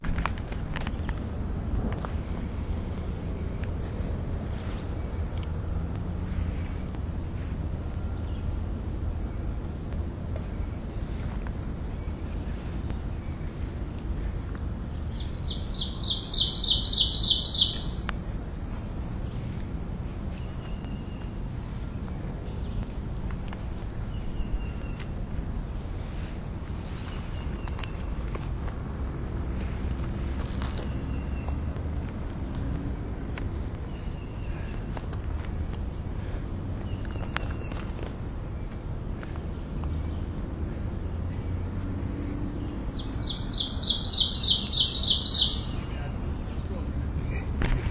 It sounds as if the bird is repeatedly saying ‘tea-cher,’ getting louder with each note.
It’s an ovenbird, a bird known for an over-sized voice and a unique nest that looks like an old-fashioned outdoor oven, after which the bird is named.